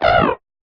eiscue_ambient.ogg